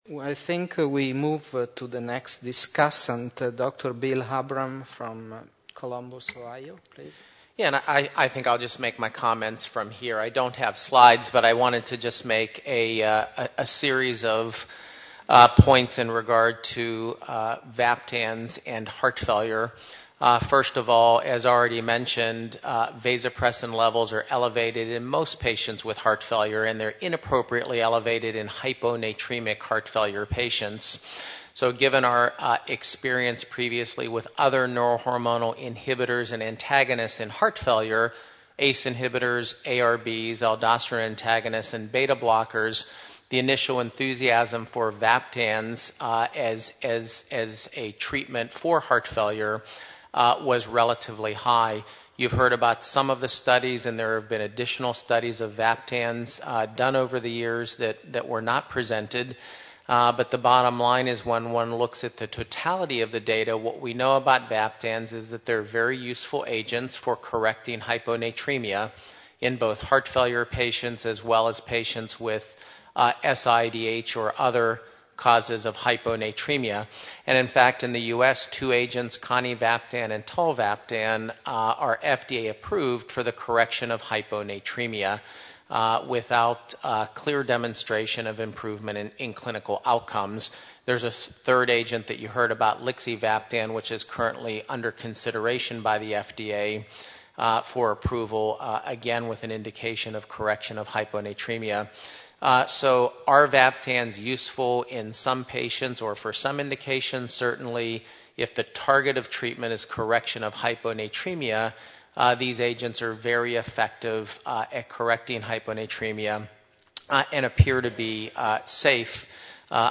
Cardiovascular Clinical Trialists (CVCT) Forum – Paris 2012 - Debate Session 5 : The Vaptans story post-EVEREST